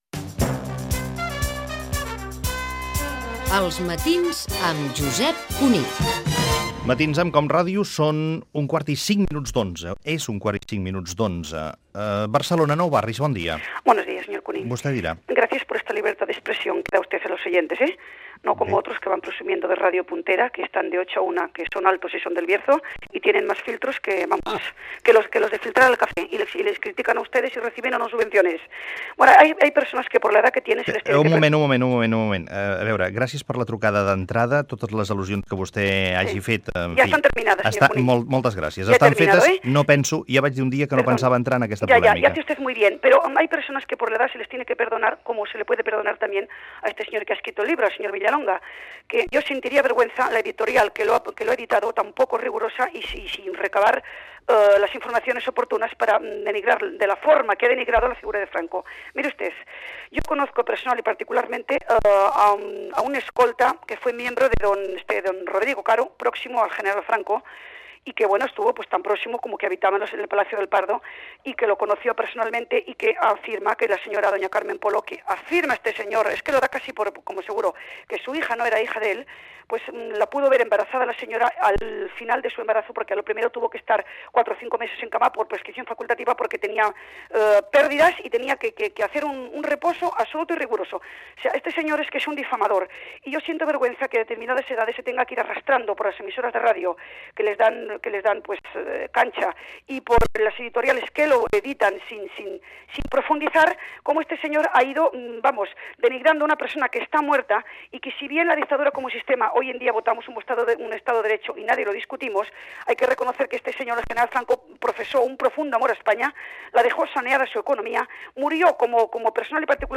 participació d'una oient que expressa la seva opinió favorable al dictador Francisco Franco
Info-entreteniment